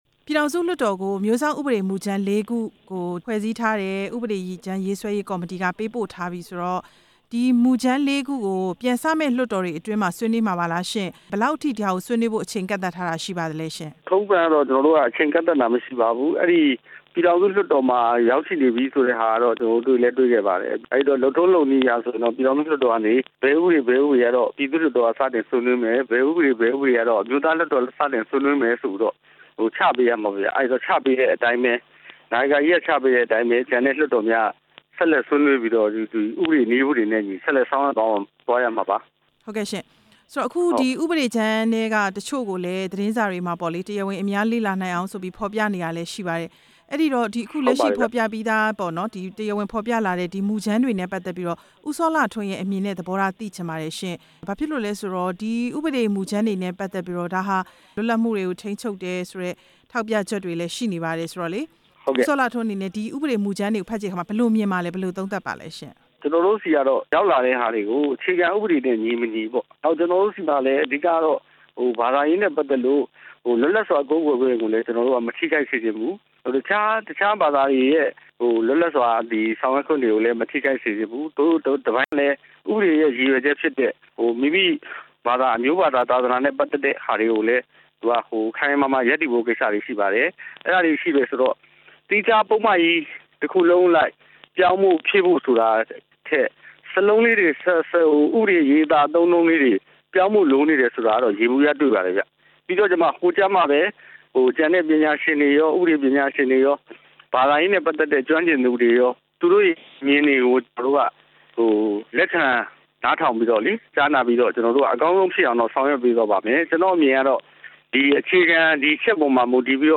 အမျိုးစောင့် ဥပဒေမူကြမ်းလေးခု ဥပဒေပြဌာန်းနိုင်ရေး မေးမြန်းချက်